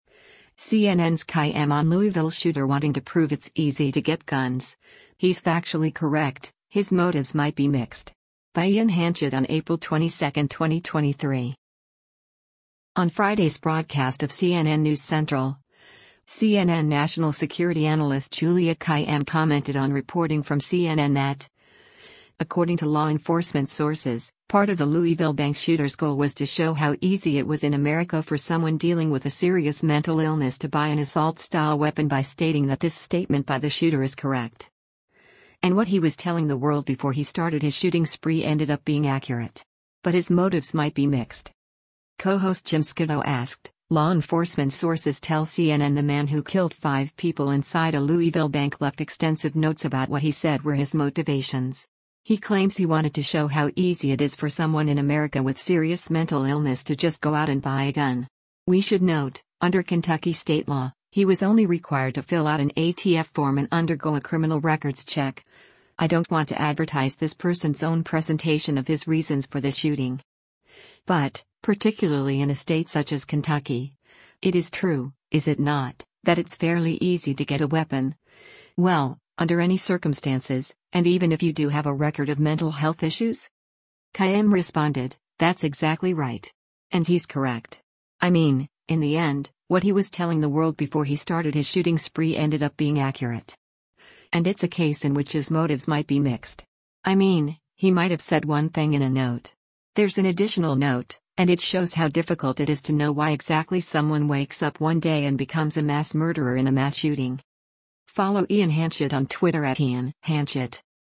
On Friday’s broadcast of “CNN News Central,” CNN National Security Analyst Juliette Kayyem commented on reporting from CNN that, according to law enforcement sources, part of the Louisville bank shooter’s “goal was to show how easy it was in America for someone dealing with a serious mental illness to buy an assault-style weapon” by stating that this statement by the shooter is “correct.”